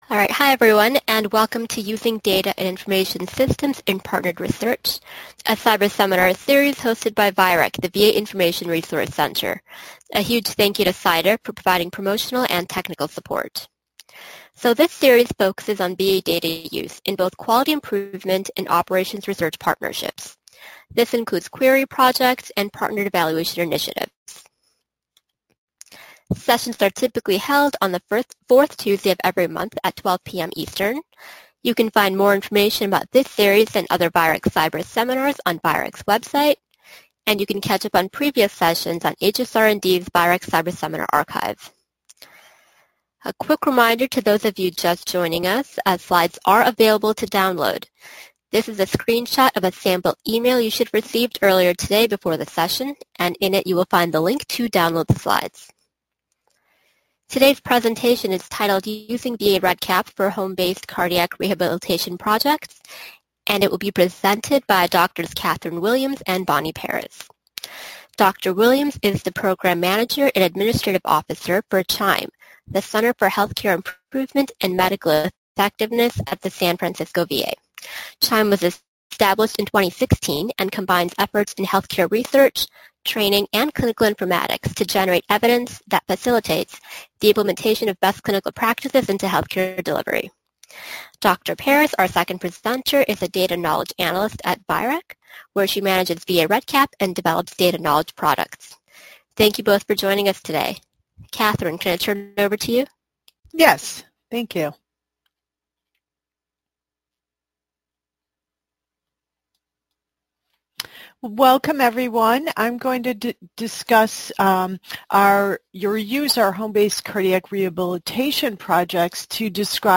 Description: VA REDCap is a web application for building surveys and databases. This presentation uses a case study approach to illustrate features used for administering online patient questionnaires, restricting data access, and creating data reports for research purposes, program management, and process improvement.